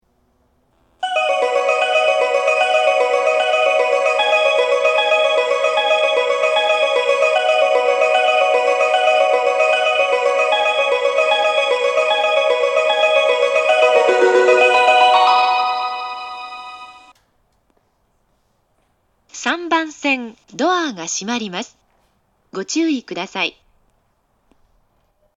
スピーカーが上下兼用の為、互いの放送が被りやすいです。
発車メロディー
フルコーラスです。